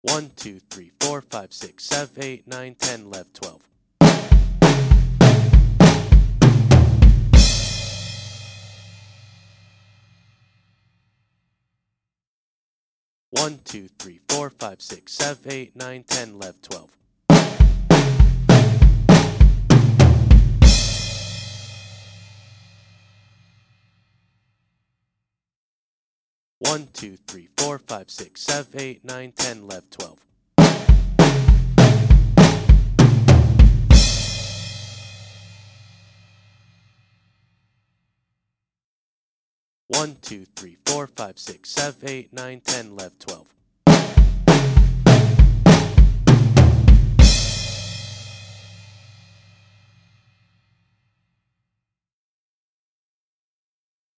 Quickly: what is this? The Fill: